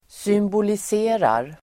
Uttal: [symbolis'e:rar]